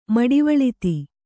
♪ maḍivaḷiti